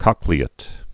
(kŏklē-ĭt, -āt, kōklē-) also coch·le·at·ed (-ātĭd)